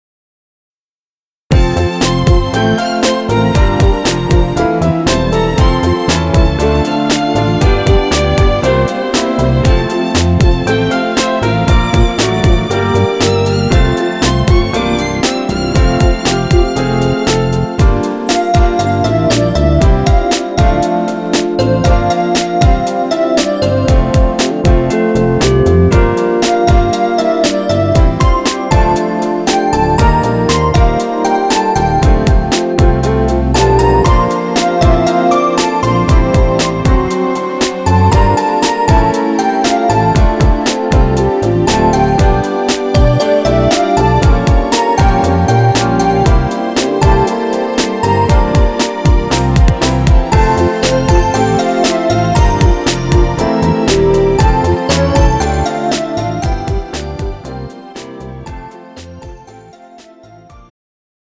음정 멜로디MR
장르 축가 구분 Pro MR